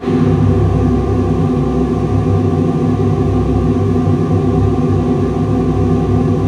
WAV · 558 KB · 單聲道 (1ch)
乐器类